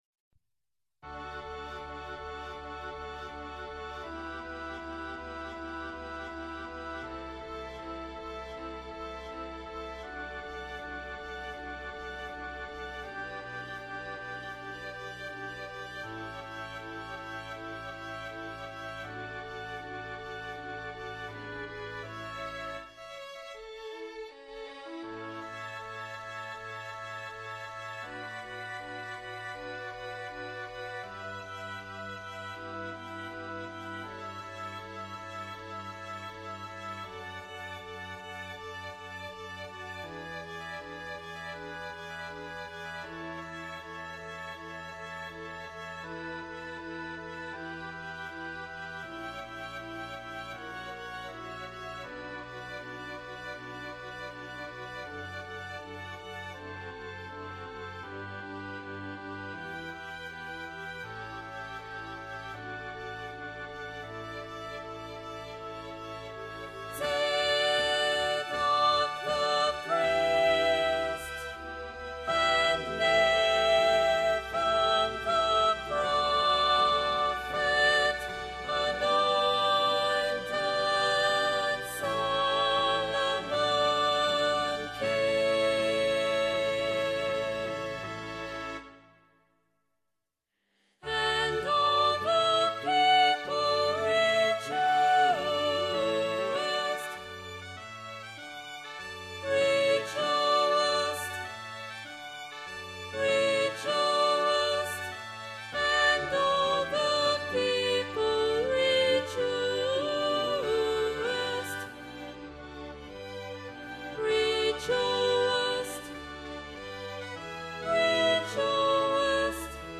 Emphasised voice and other voices